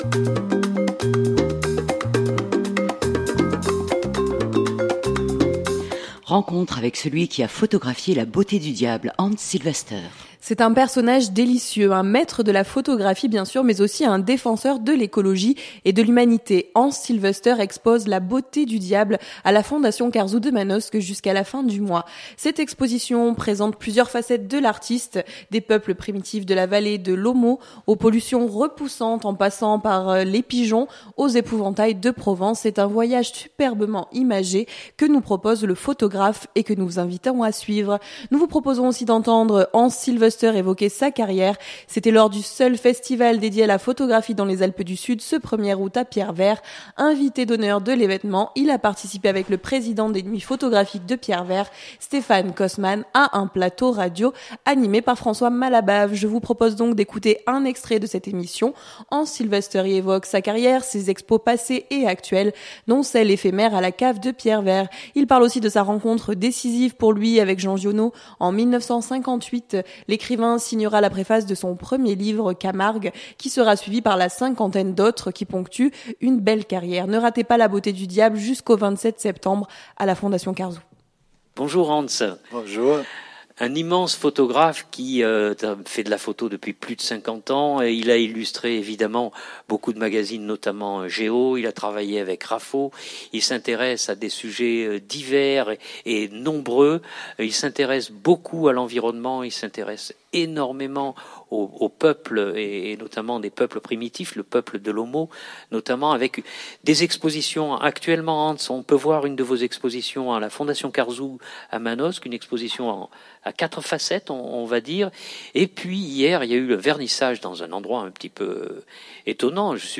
Nous vous proposons aussi d’entendre Hans Silvester évoquer sa carrière, c’était lors du seul festival dédié à la photographie dans les Alpes du Sud ce 1° août à Pierrevert.
Je vous propose d’écouter un extrait de cette émission. Hans Silvester y évoque sa carrière, ses expos passées et actuelles dont celle éphémère à la cave de Pierrevert. Il parle aussi de sa rencontre décisive pour lui avec Jean Giono en 1958.